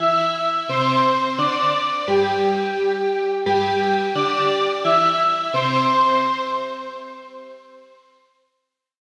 schoolBell.ogg